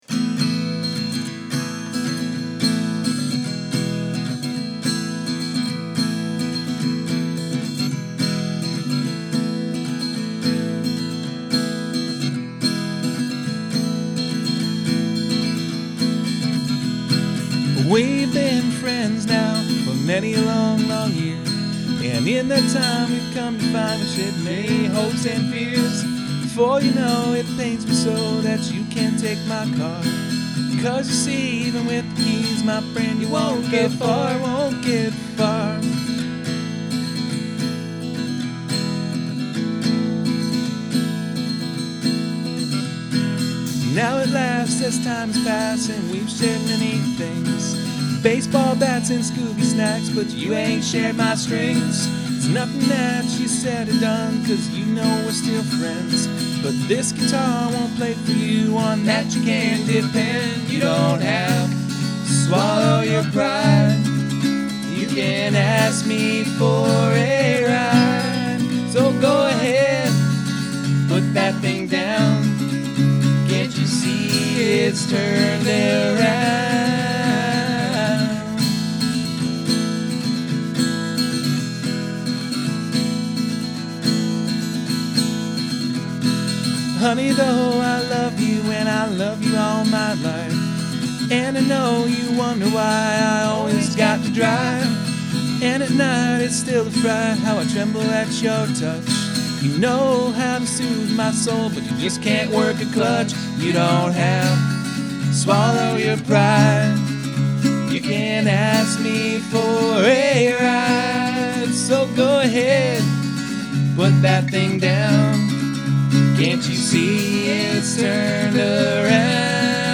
I’m a guitarist, bassist and singer interested in blues, garage, punk, R&B, and funk.
I make music in storage unit here in Nashville Tennessee.